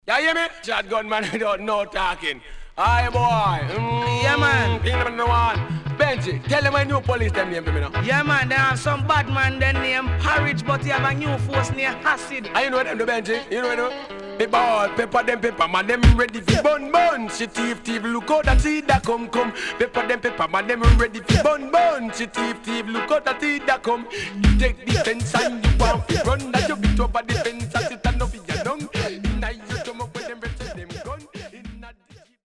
HOME > Back Order [DANCEHALL 7inch]  >  RECOMMEND DANCEHALL
Nice Deejay
SIDE A:少しノイズ入りますが良好です。